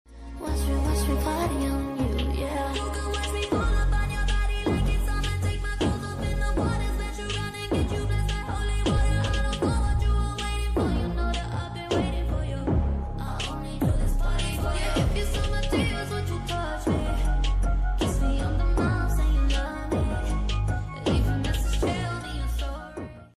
#8dmusic #8daudio